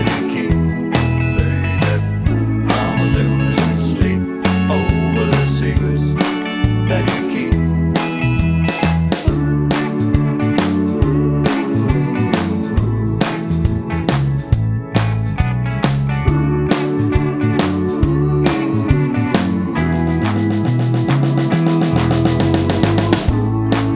Glam Rock